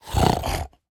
Minecraft Version Minecraft Version 1.21.5 Latest Release | Latest Snapshot 1.21.5 / assets / minecraft / sounds / mob / piglin_brute / idle3.ogg Compare With Compare With Latest Release | Latest Snapshot